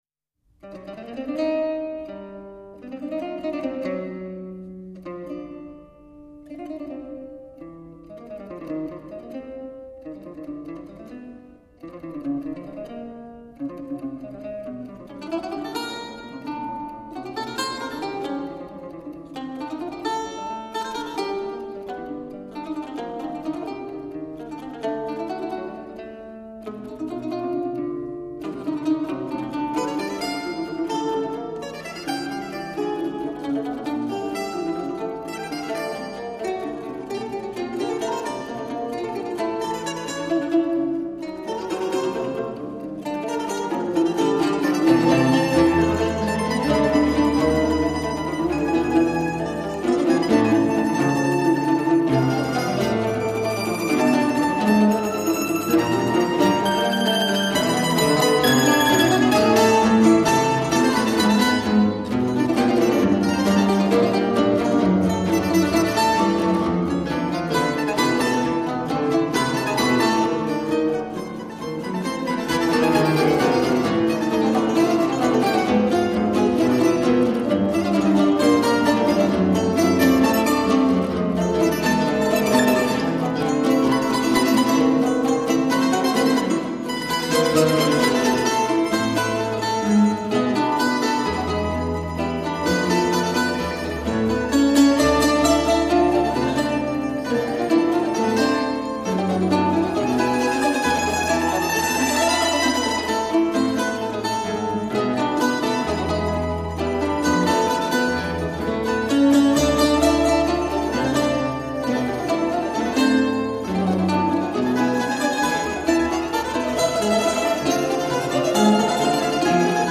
新世纪音乐
录音棚：中央电视台480平方米录音棚